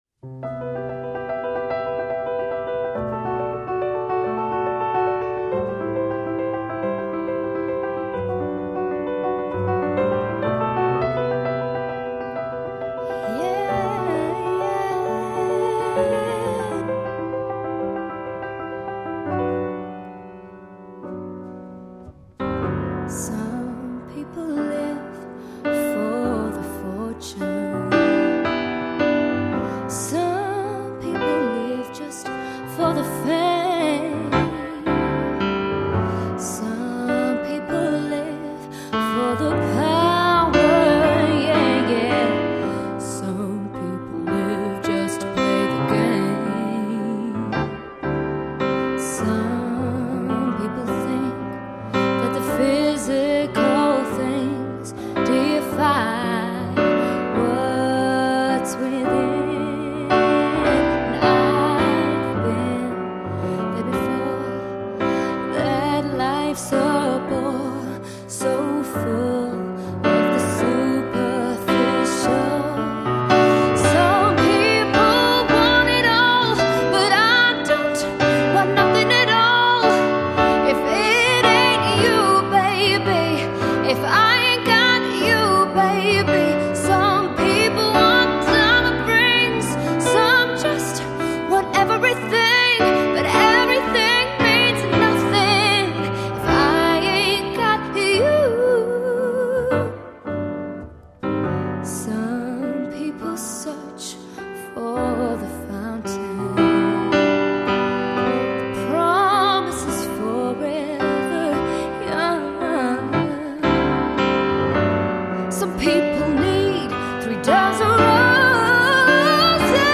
Vocals, Guitar